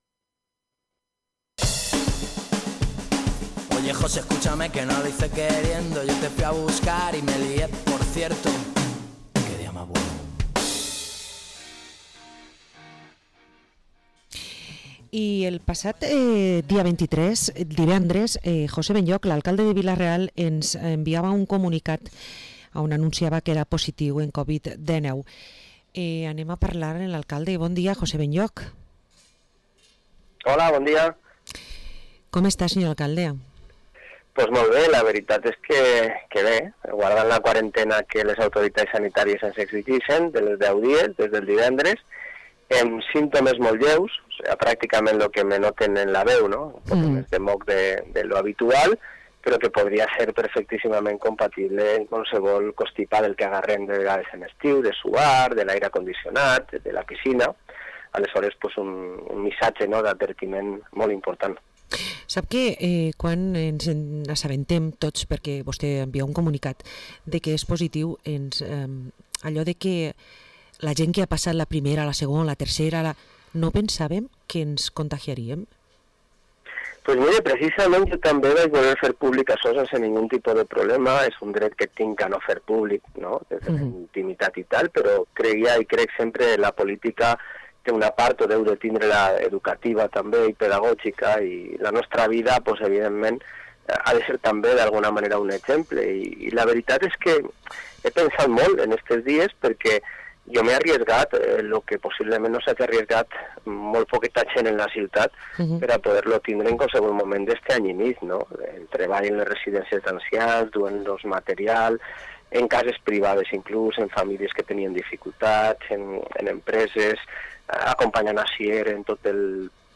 Entrevista a José Benlloch, Alcade de Vila-real